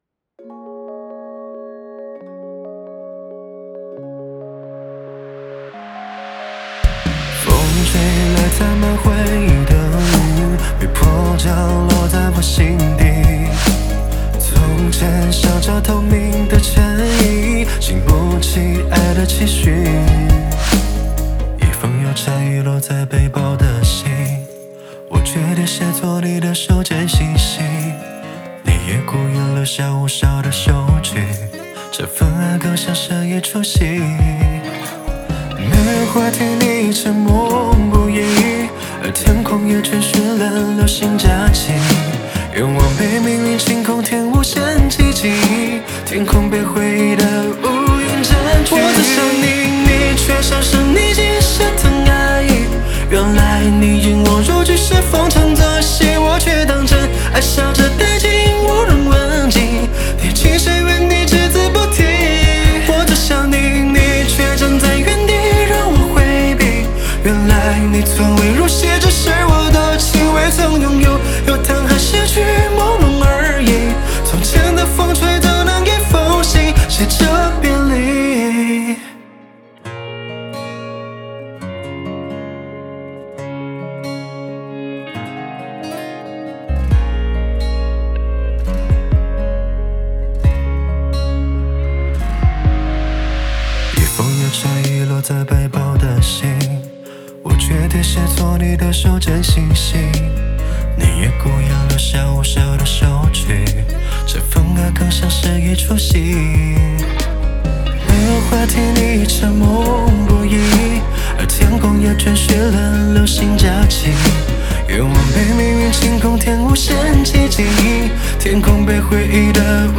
很干净的男声